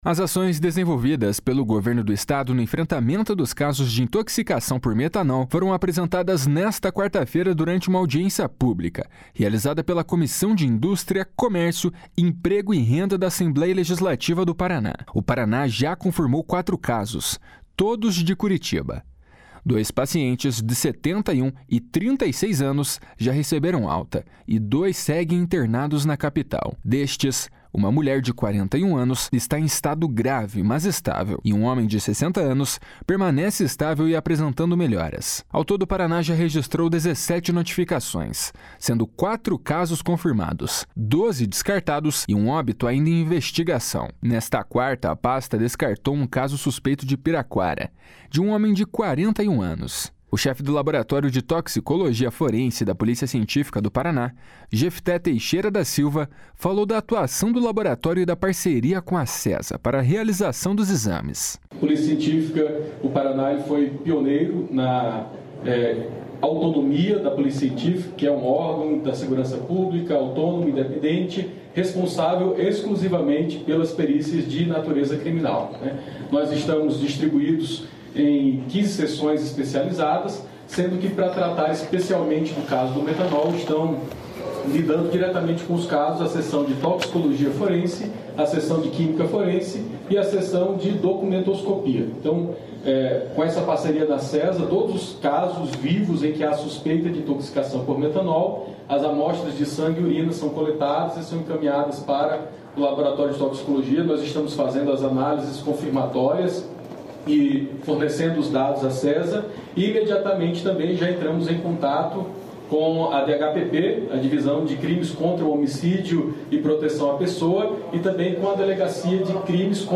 As ações desenvolvidas pelo Governo do Estado no enfrentamento dos casos de intoxicação por metanol foram apresentadas nesta quarta-feira durante uma audiência pública realizada pela Comissão de Indústria, Comércio, Emprego e Renda da Assembleia Legislativa do Paraná.